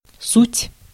Ääntäminen
US : IPA : /ɑɹ/ UK : IPA : /ɑː(ɹ)/